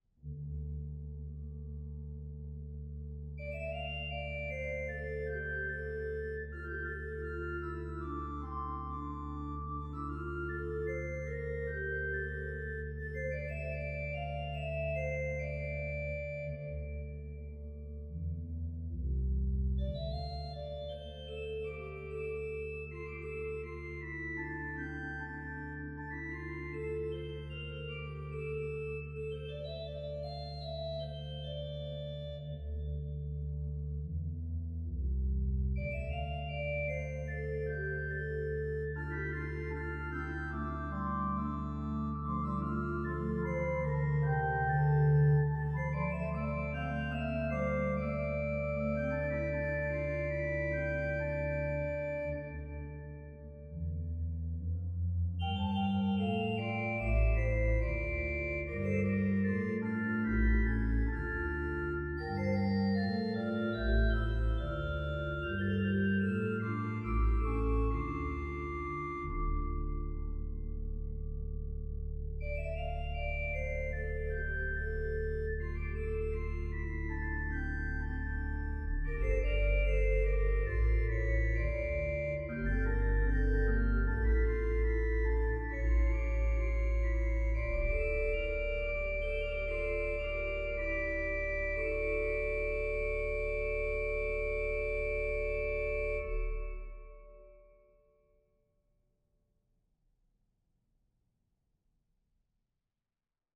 Voicing: Organ 2-staff